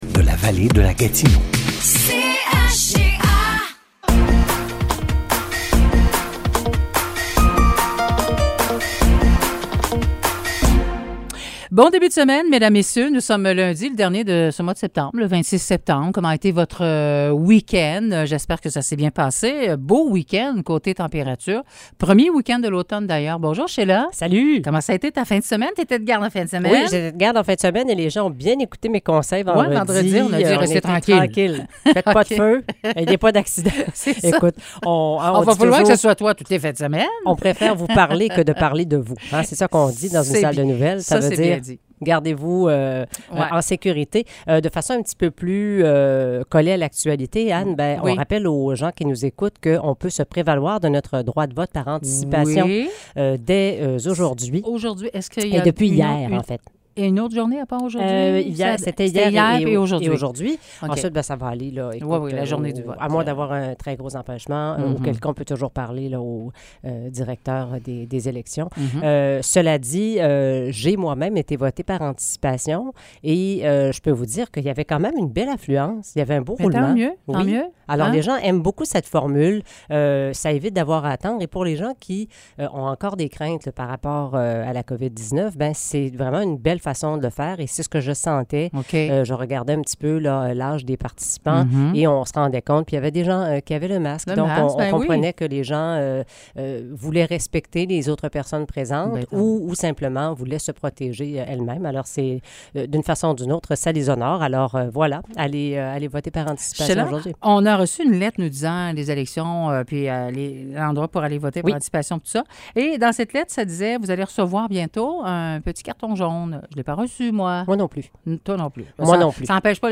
Nouvelles locales - 26 septembre 2022 - 9 h